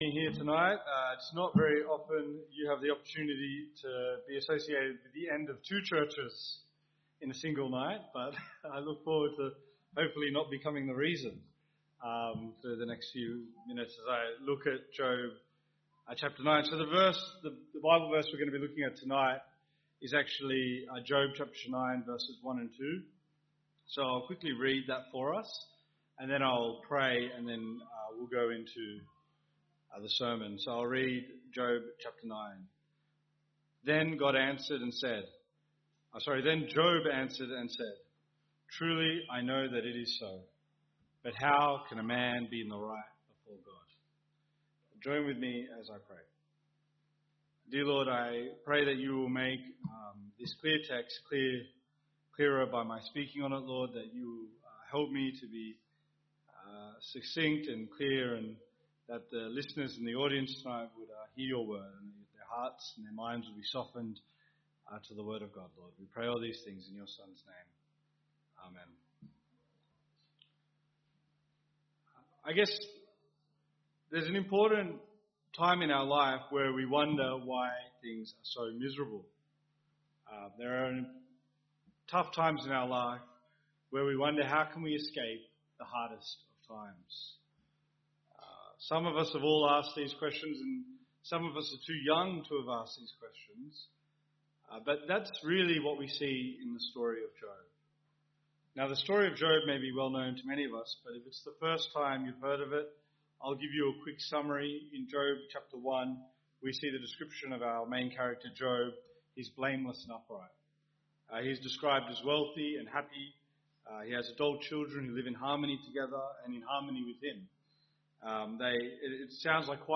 Sermons , Visiting Speakers